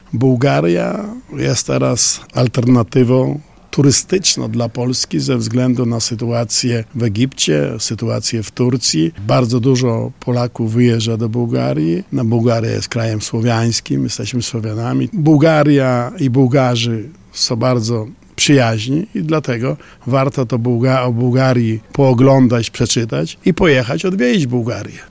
Spotkanie odbywa się w Lubelskim Centrum Konferencyjnym w Lublinie.